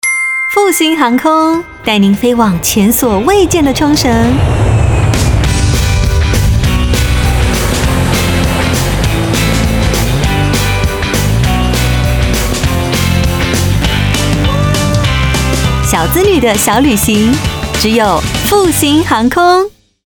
台語配音 國語配音 女性配音員